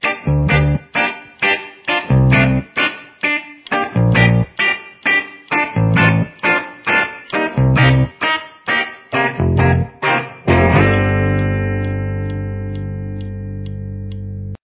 blends rock with elements of reggae
Here’s what the guitar and bass sound like together: